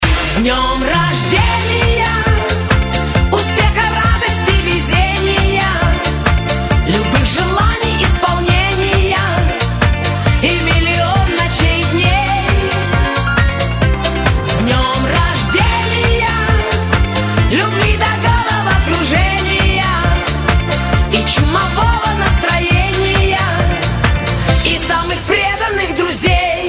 Танцевальные рингтоны
Эстрадные
Поп